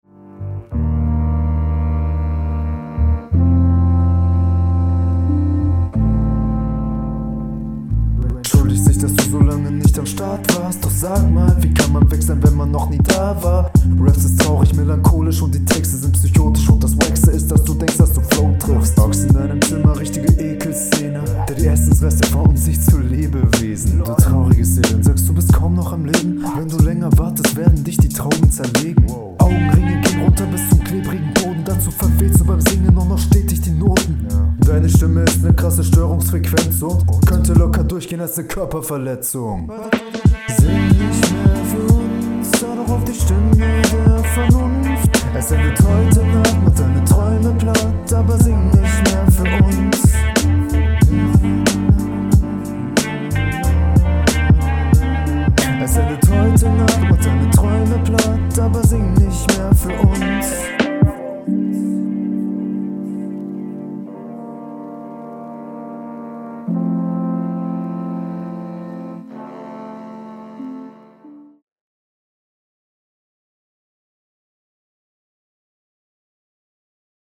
Flow geht echt voll klar und textlich auch Bezug da.